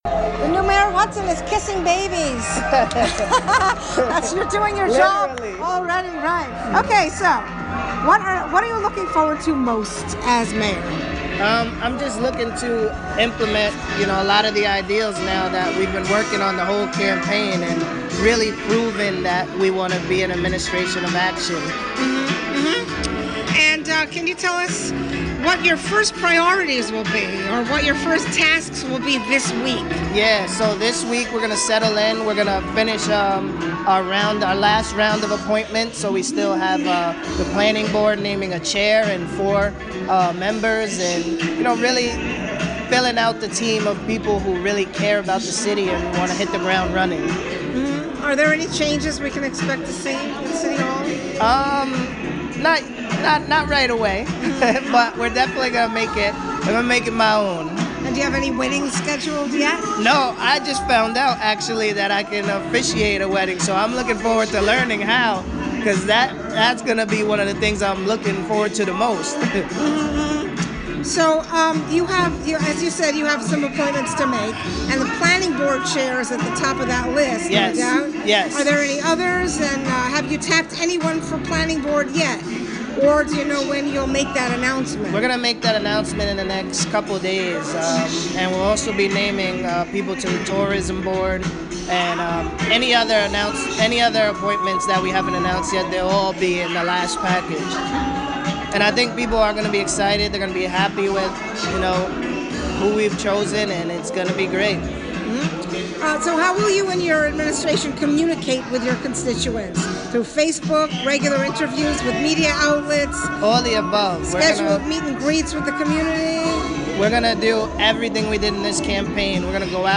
Audio Feature: Hudson Mayor Kamal Johnson